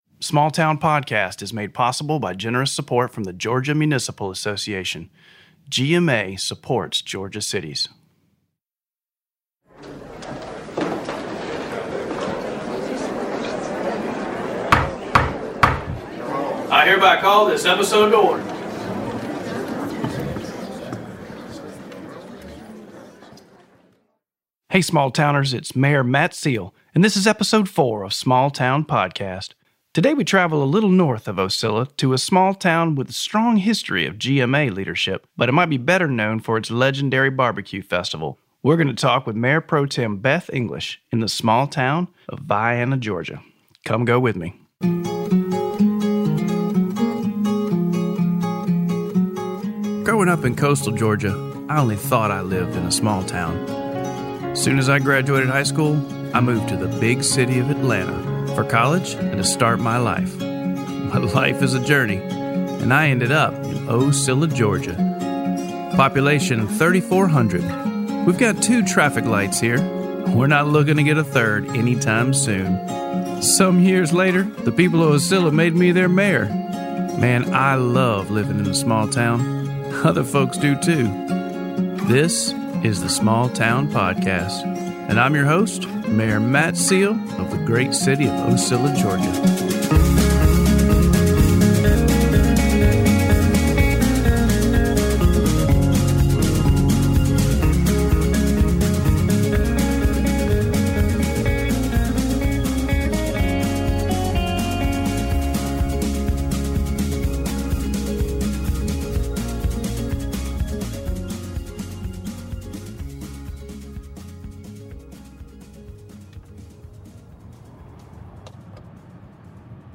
Facebook Twitter Headliner Embed Embed Code See more options Ocilla Mayor and Small Town Podcast Host Matt Seale travels to Vienna, Georgia for a conversation with longstanding Councilmember Beth English. The two discuss how English came to Vienna and quickly got involved with community groups and historic preservation, eventually being elected to City Council 5 times.